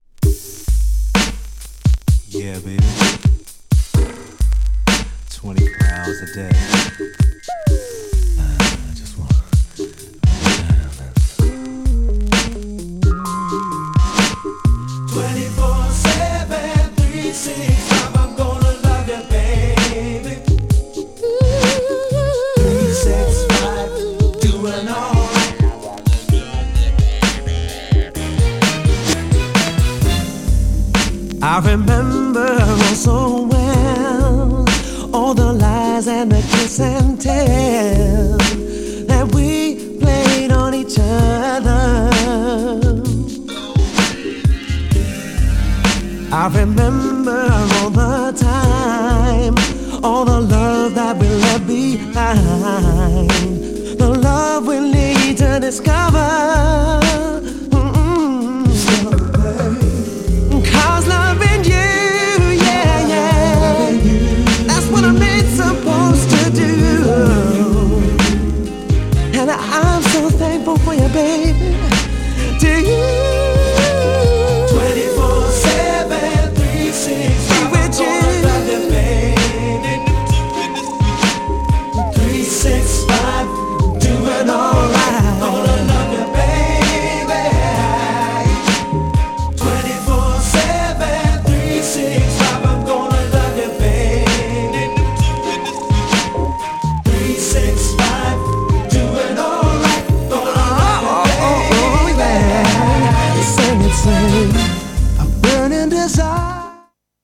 GENRE R&B
BPM 101〜105BPM